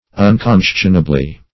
Un*con"scion*a*bly, adv.
unconscionably.mp3